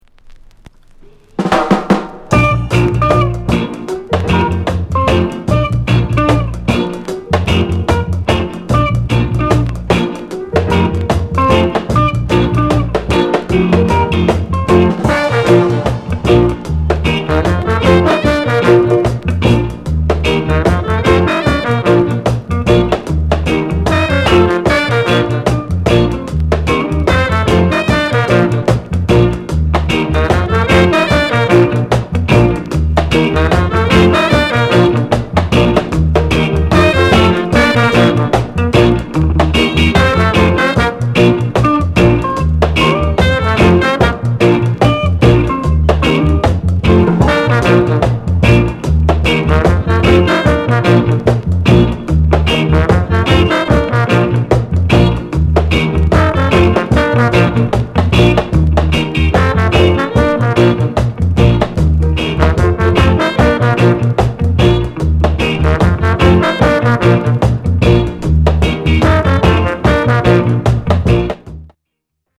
BIG RIDDIM AFRICAN BEAT